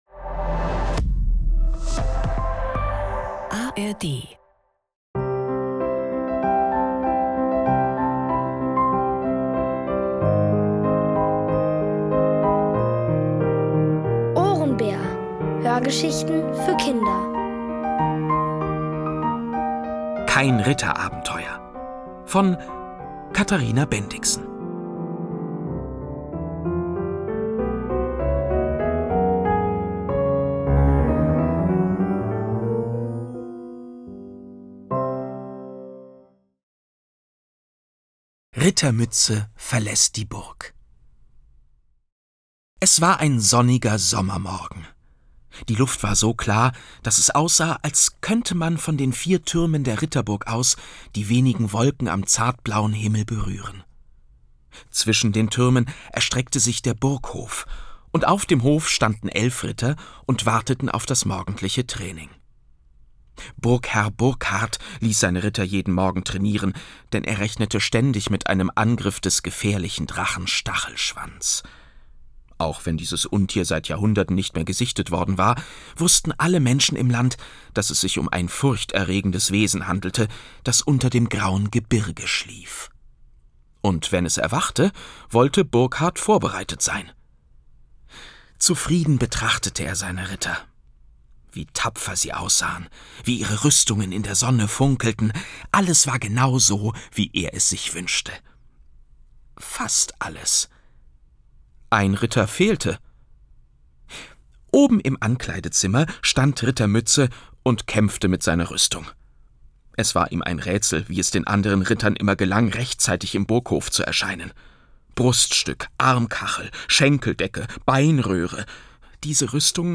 Kein Ritterabenteuer | Die komplette Hörgeschichte! ~ Ohrenbär Podcast